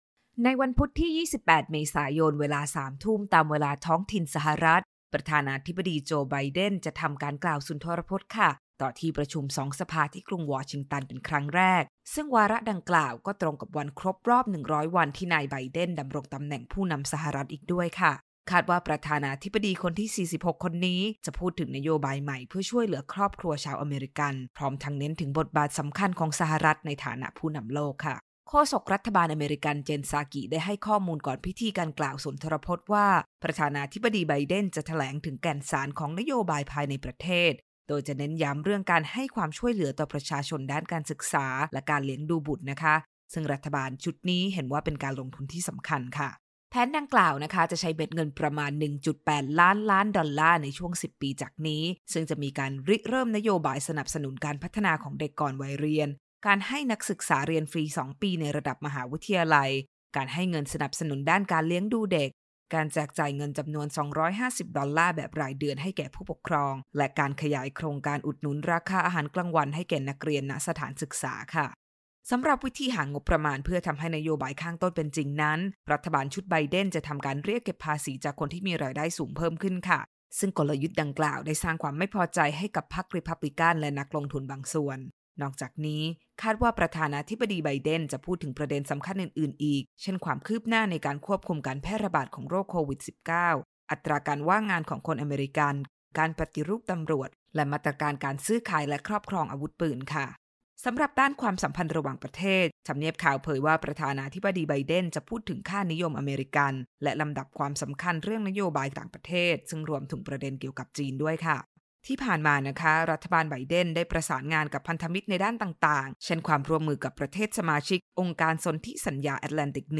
Biden Joint Address Speech